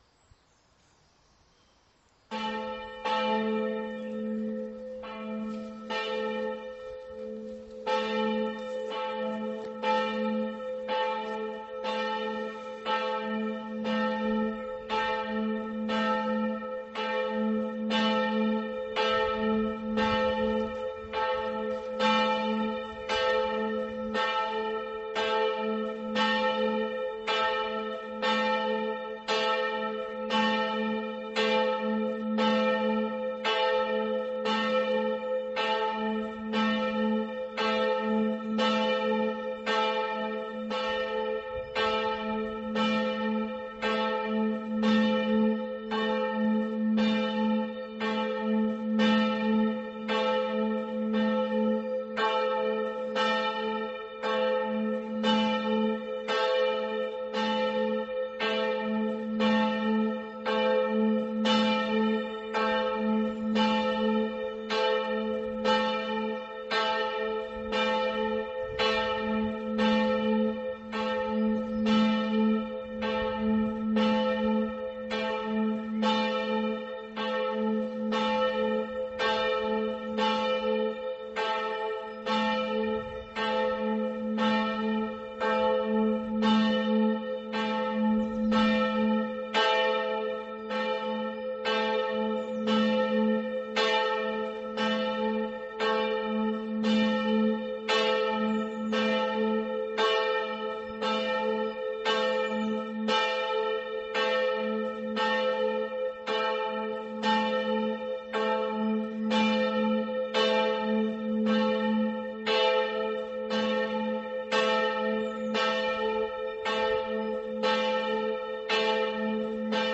Wie an jedem Abend läuten um 19.00 Uhr die
Glocken unserer Christuskirche (bitte anklicken!) und laden uns ein  zum gemeinsamen Gebet.
Gebets-Glocke_Christuskirche.mp3